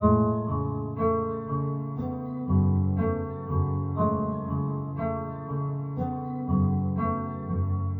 120_SadBoy_B.wav